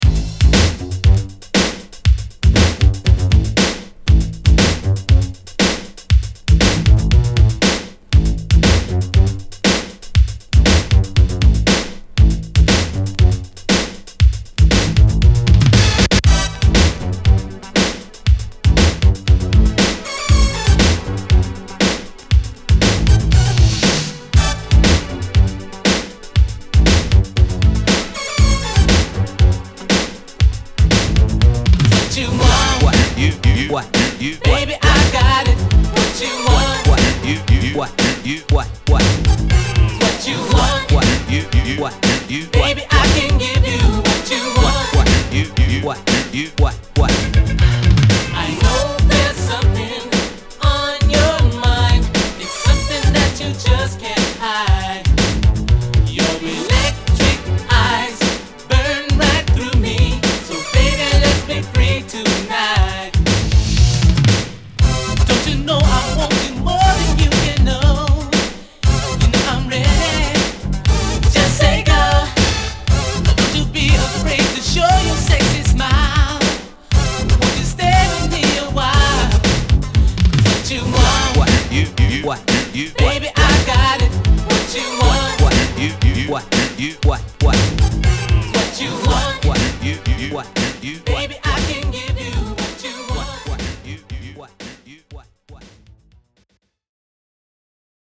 ・ DISCO 80's 12'